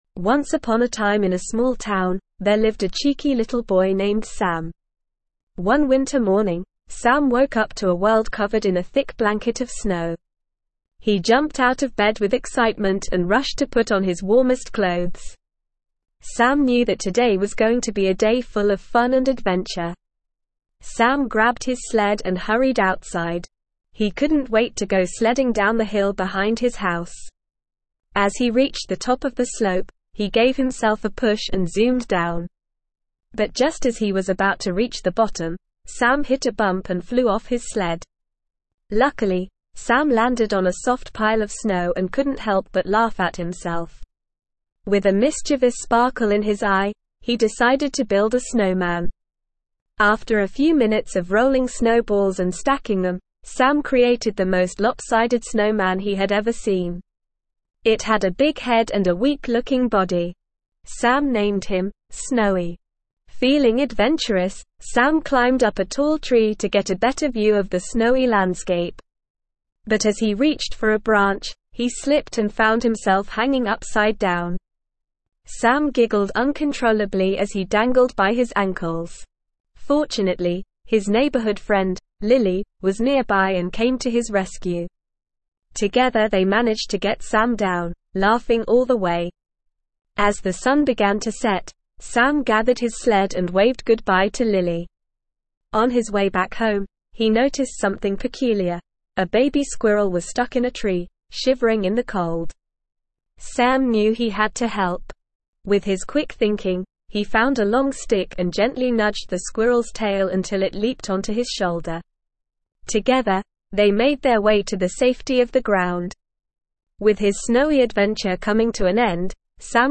ESL-Short-Stories-for-Kids-Advanced-NORMAL-Reading-Sams-Snowy-Day.mp3